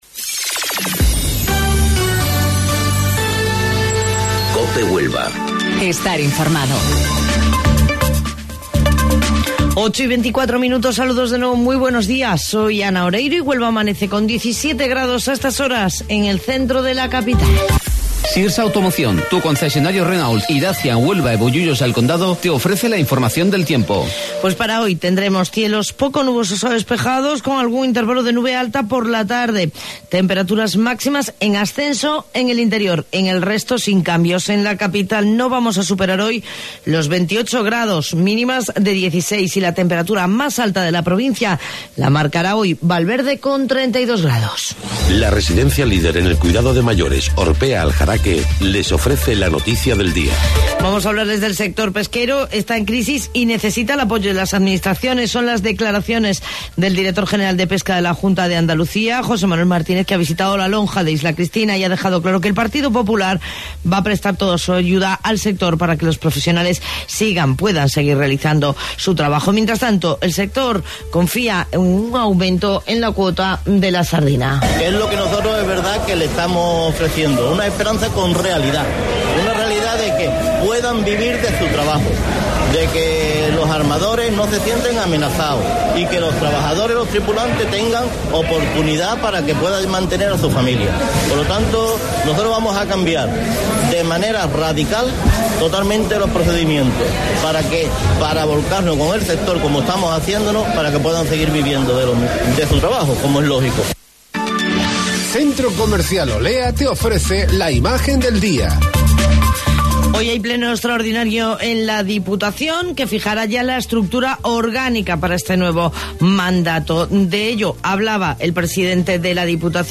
AUDIO: Informativo Local 08:25 del 9 de Julio